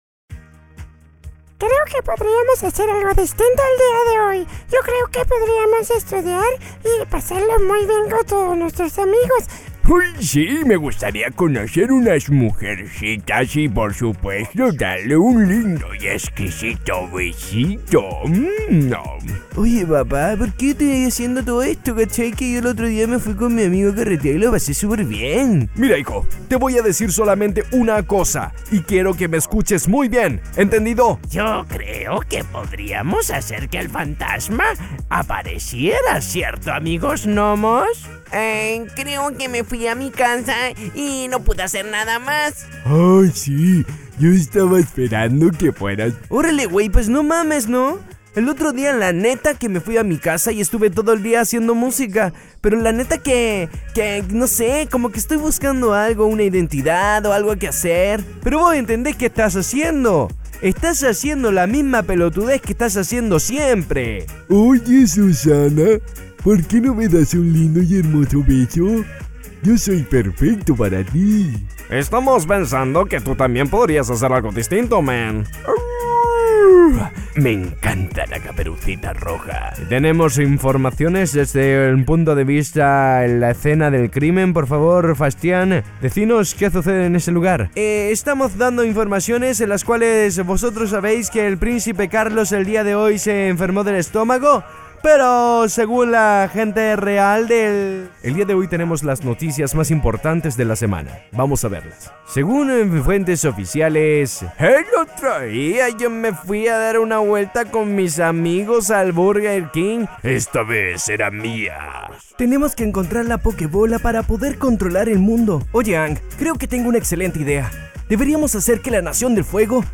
Masculino
Espanhol - América Latina Neutro